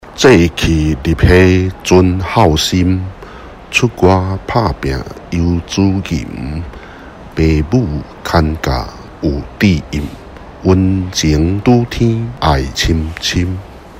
(讀頌版)